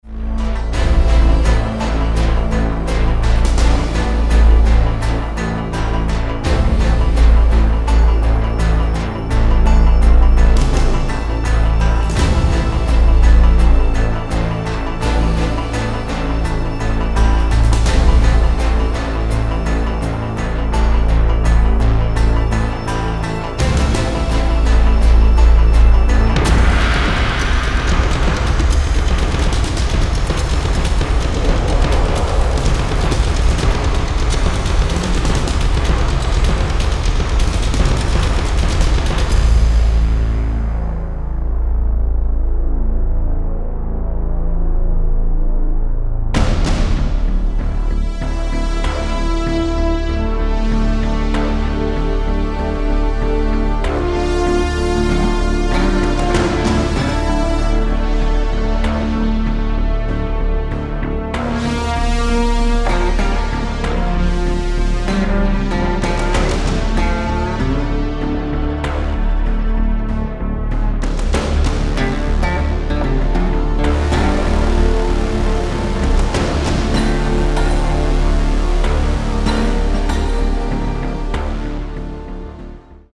Tinged with blues and Cajun stylings